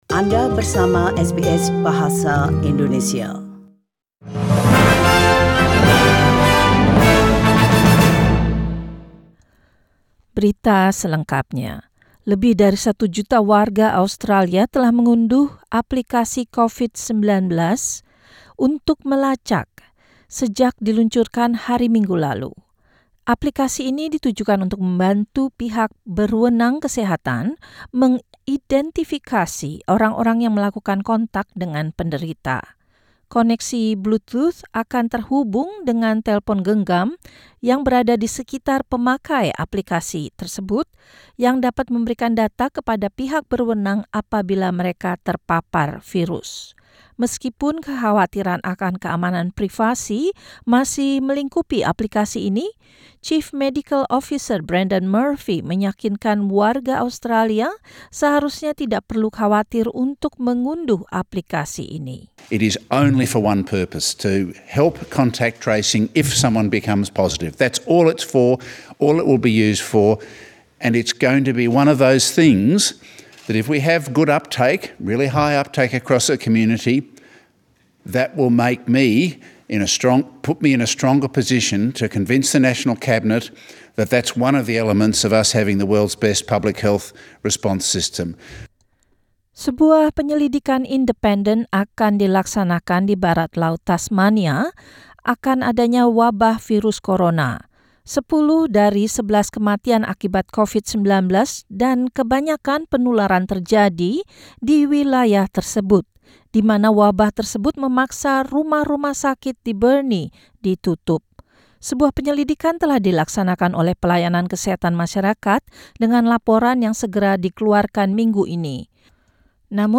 SBS Radio News in Indonesian - 27 April 2020